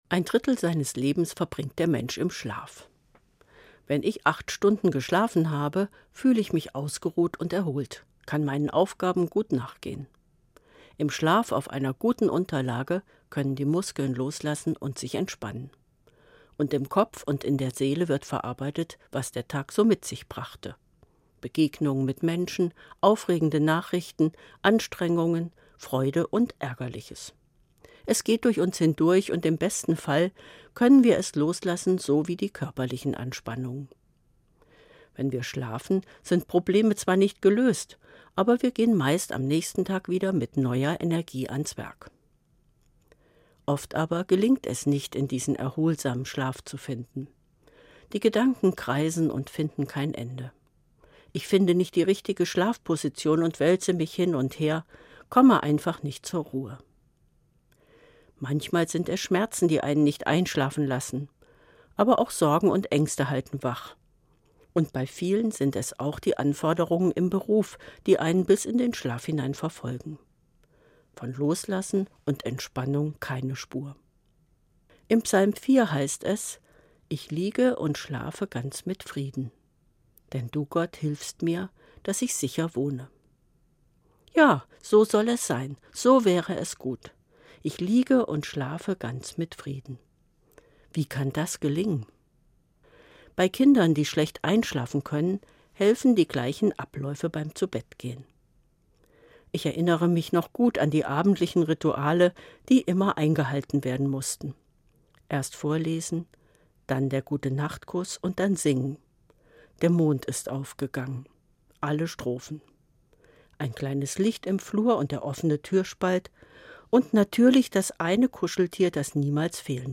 Evangelische Pfarrerin, Kassel